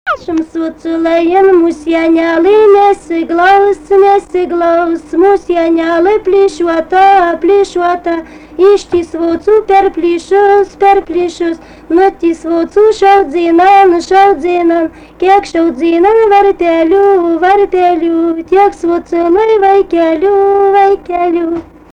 Tipas daina Erdvinė aprėptis Kriokšlys
Atlikimo pubūdis vokalinis
Pastabos 2 balsai